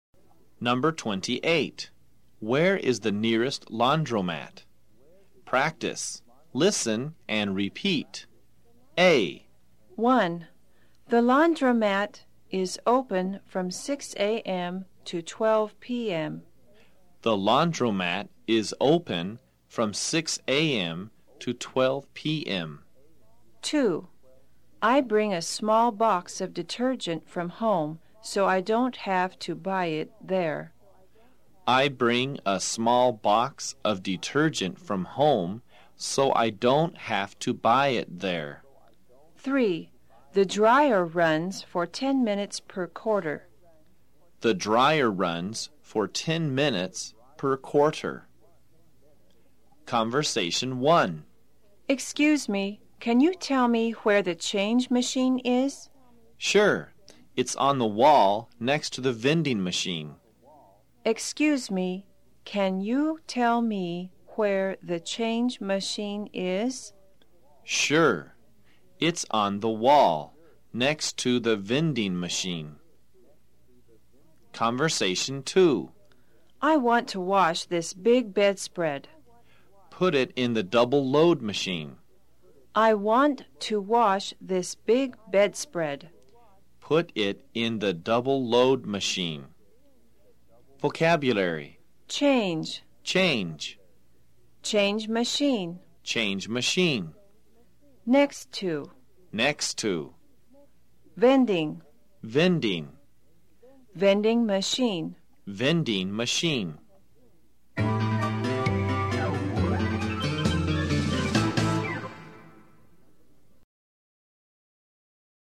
在线英语听力室快口说英语028的听力文件下载,快口说英语的每一句话都是地道、通行全世界的美国英语，是每天24小时生活中，时时刻刻都用得上的美语。